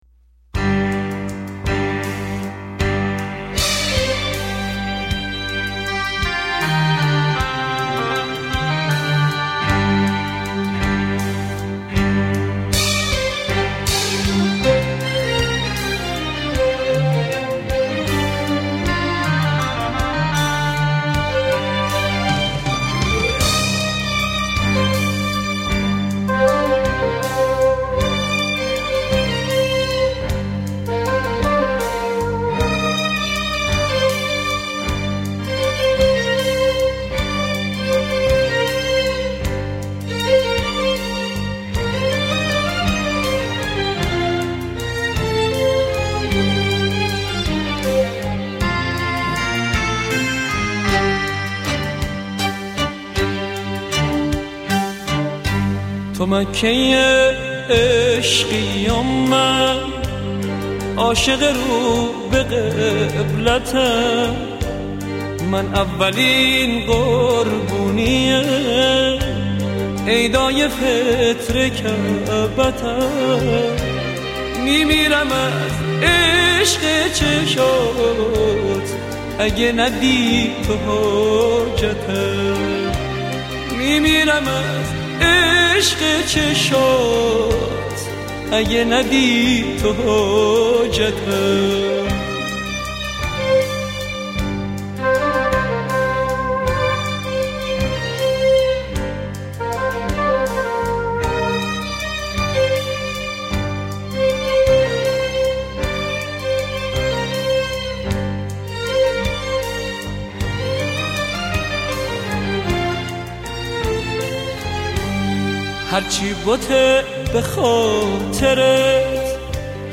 آهنگ عاشقانه آهنگ احساسی آهنگ نوستالژیک